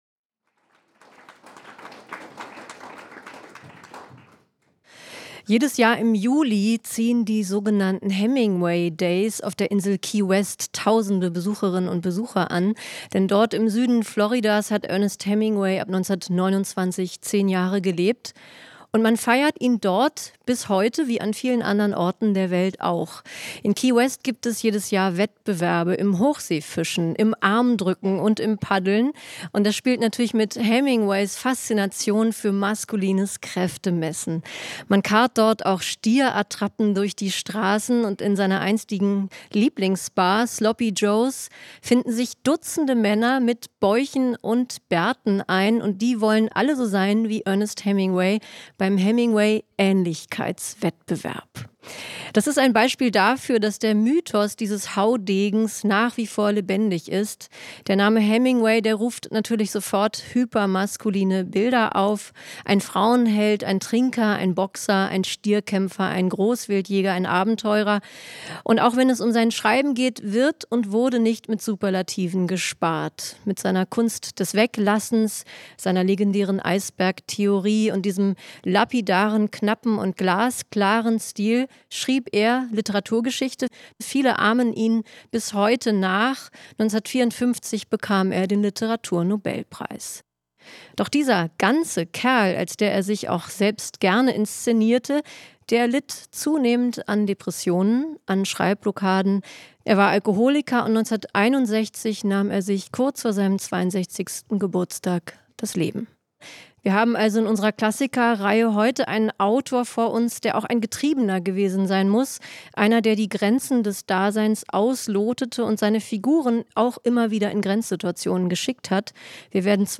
Mitarbeit Interviewte Person: Ulrike Draesner, John von Düffel